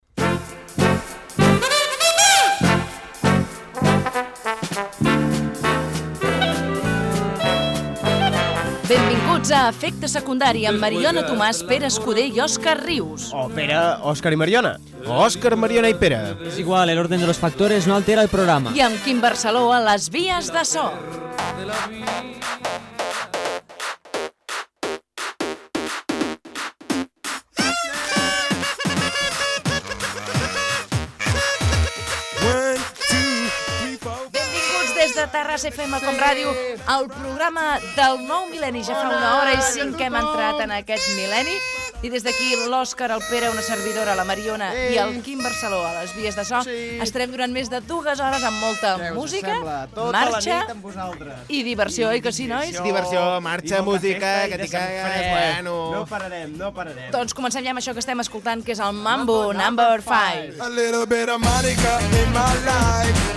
Inici d'un programa especial de cap d'any. Presentació, equip i tema musical
Entreteniment